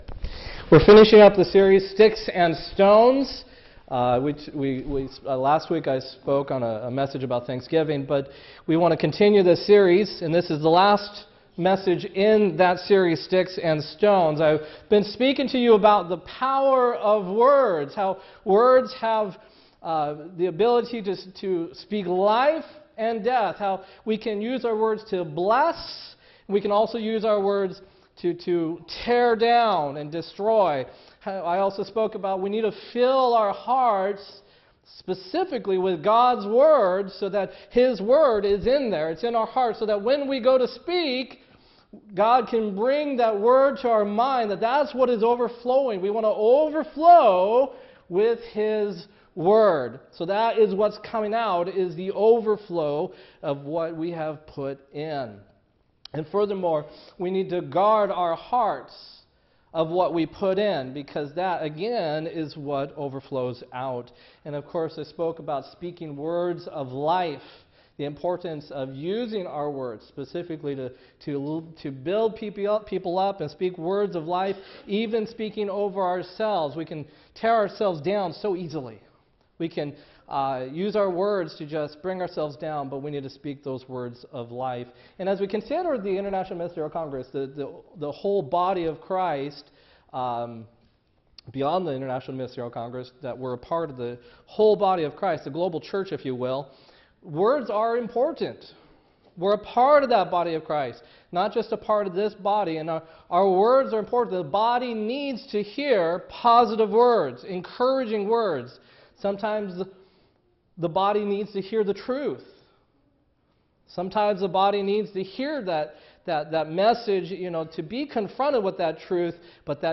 11-12-16-sermon « sermon blog